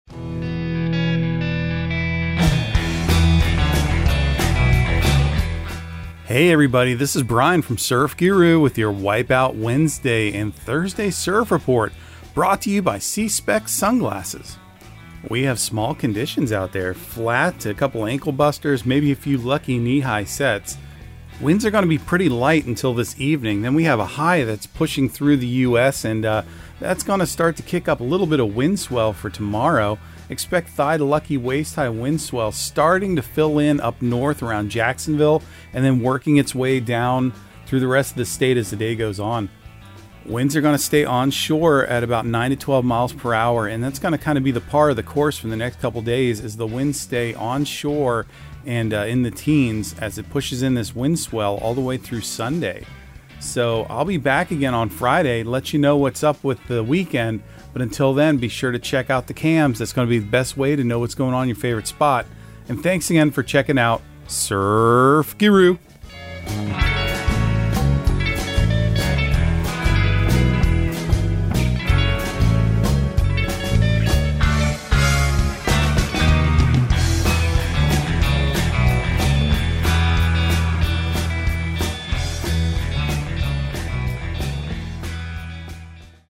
Surf Guru Surf Report and Forecast 05/10/2023 Audio surf report and surf forecast on May 10 for Central Florida and the Southeast.